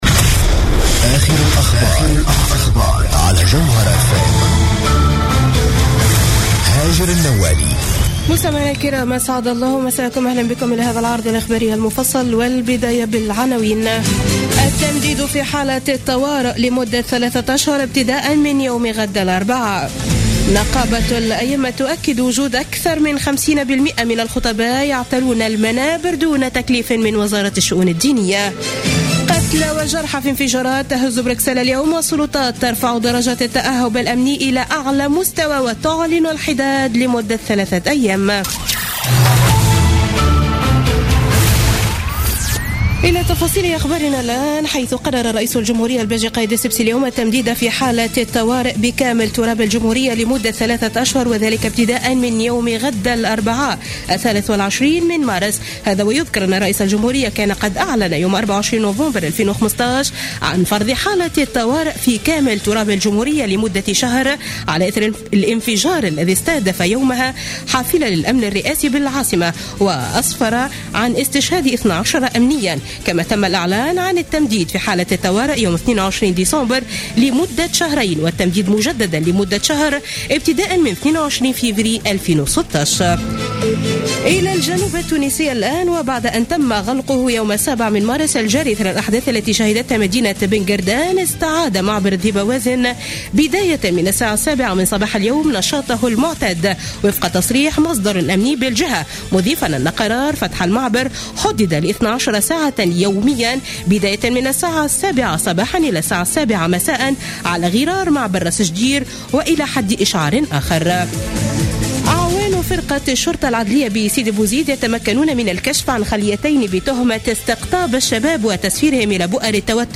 نشرة أخبار السابعة مساء ليوم الثلاثاء 22 مارس 2016